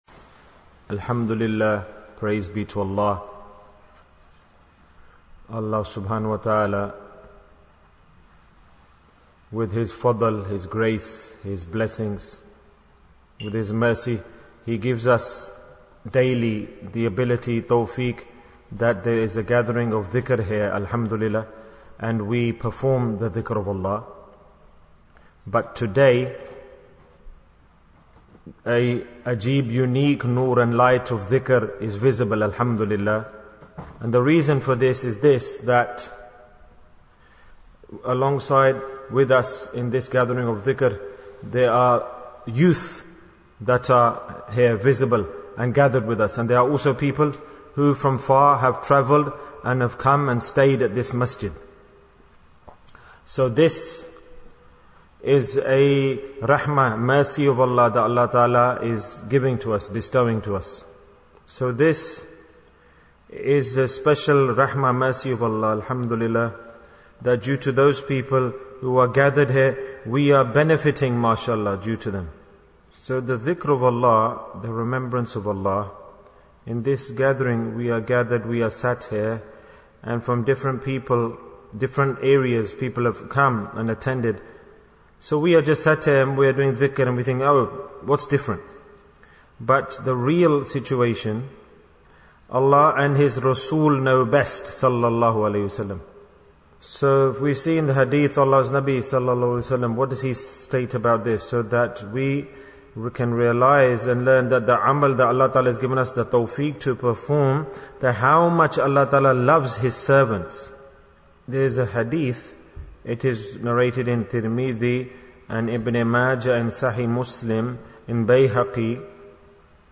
Bayans Clips Naat Sheets Store Live Rewards of Dhikrullah Bayan, 43 minutes 25th December, 2021 Click for Urdu Download Audio Comments What a delight!